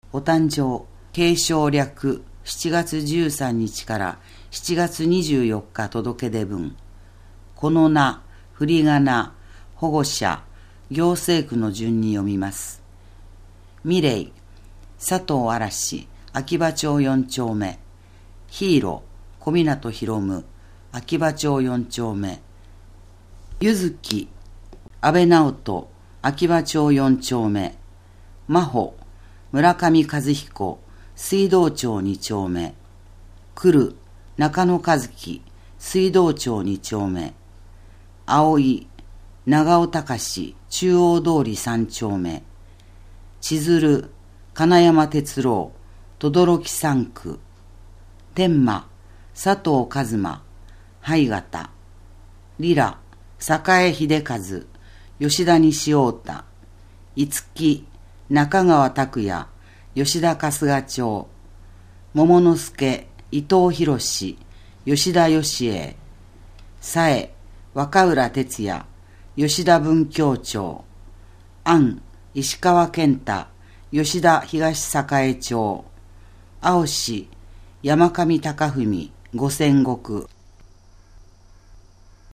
声の広報つばめ2018年8月15日号